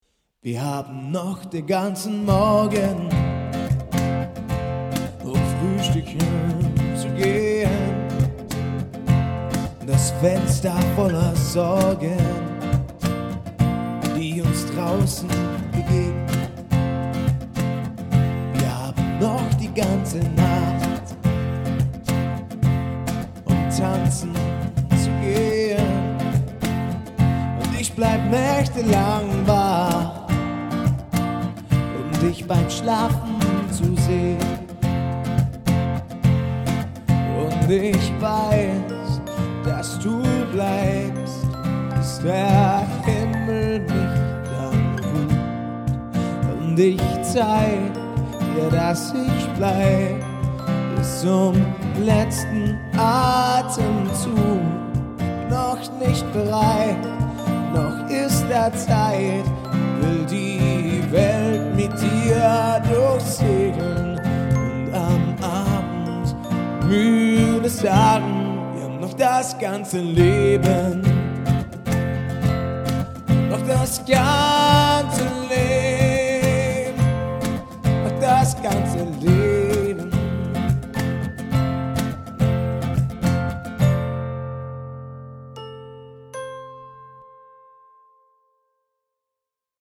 Trio